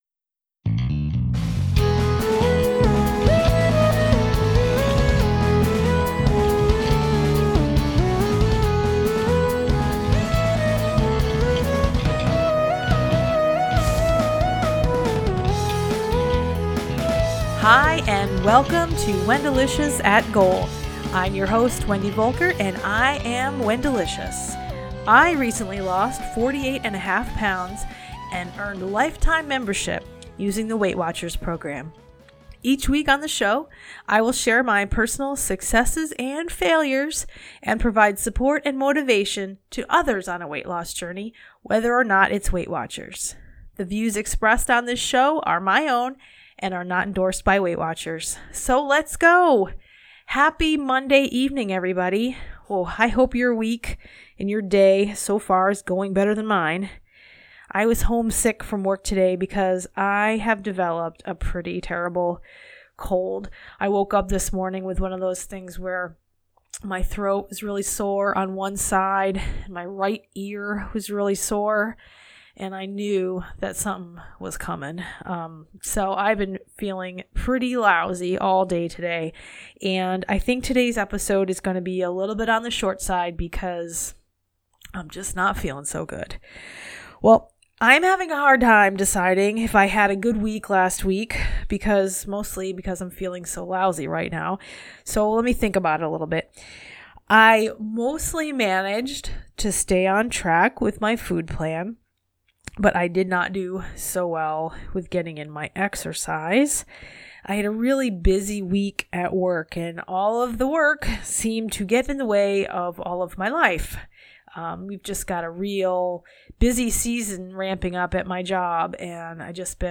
In this episode: Lots of sniffling.